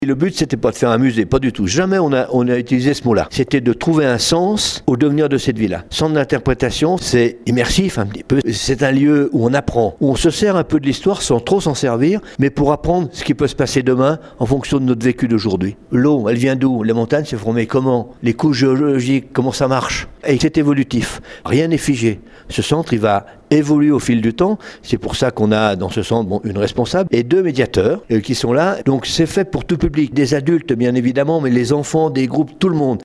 L’idée n’était donc pas d’en faire un musée comme nous le confirme Marin Gaillard le maire de St Pierre en Faucigny